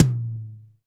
Index of /90_sSampleCDs/Roland L-CD701/TOM_Real Toms 1/TOM_Ac.Toms 1
TOM RLTOM1DL.wav